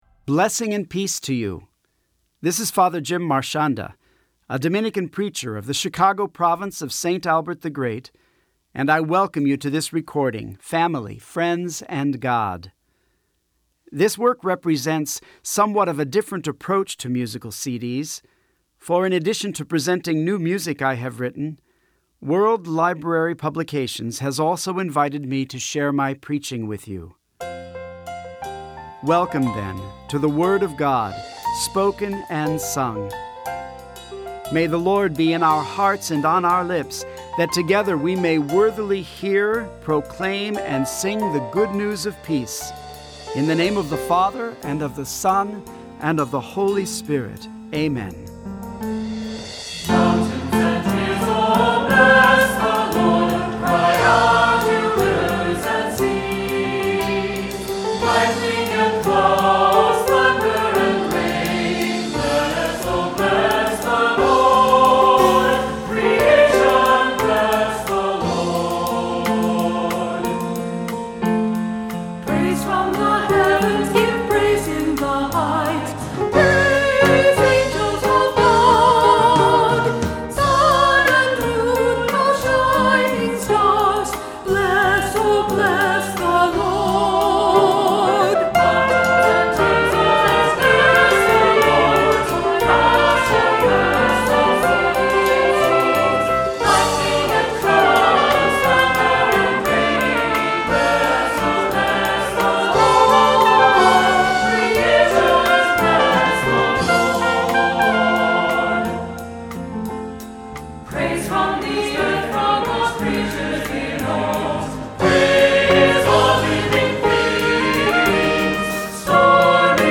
Voicing: SATB, cantor, assembly